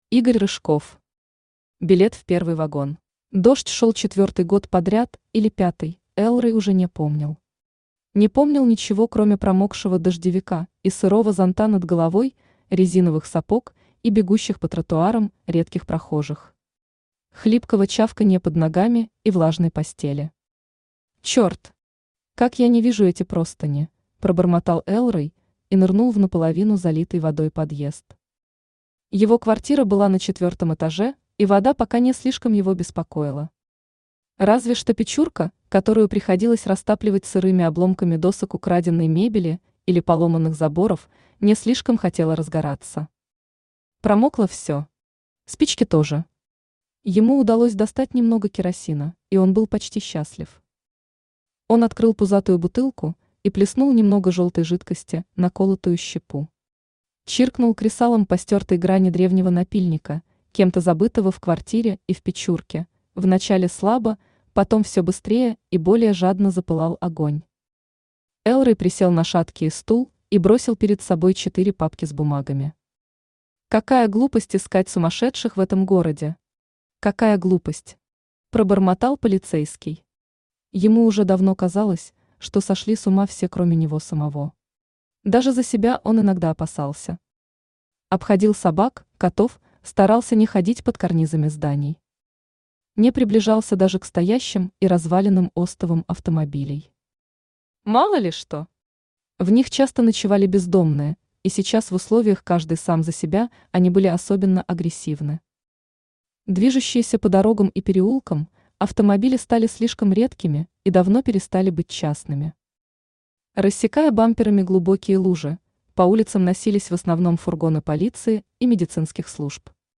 Аудиокнига Билет в первый вагон | Библиотека аудиокниг
Aудиокнига Билет в первый вагон Автор Игорь Рыжков Читает аудиокнигу Авточтец ЛитРес.